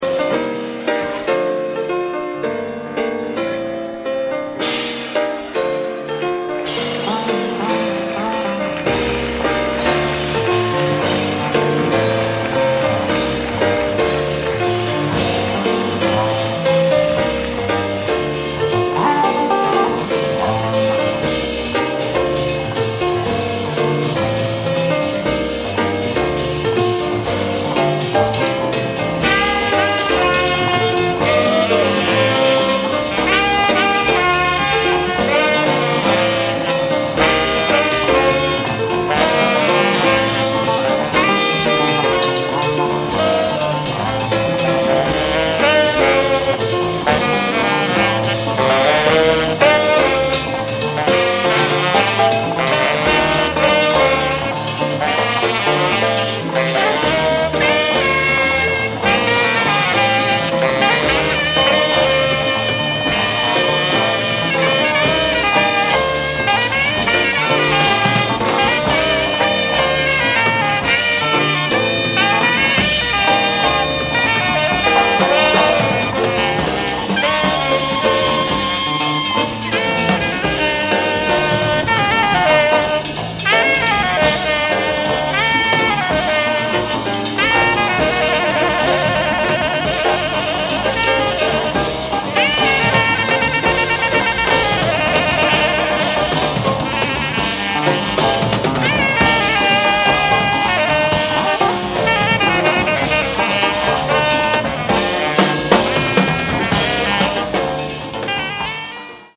Recorded live at Montreux Jazz Festival July 1978.